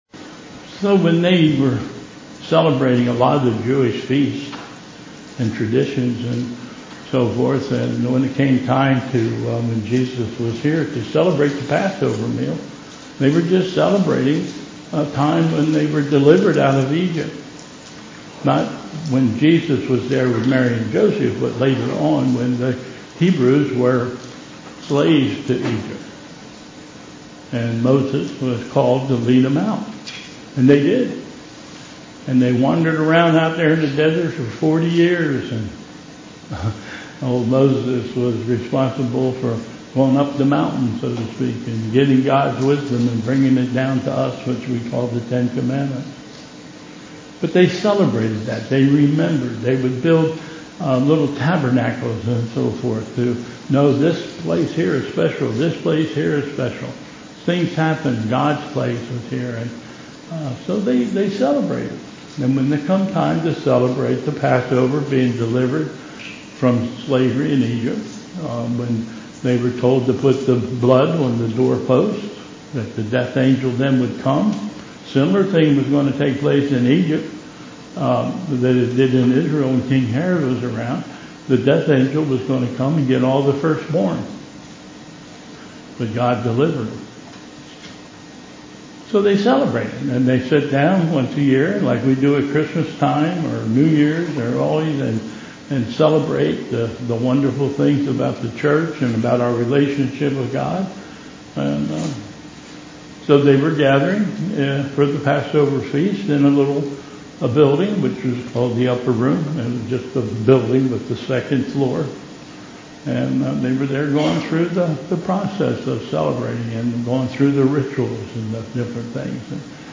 Bethel Church Service
Service of Communion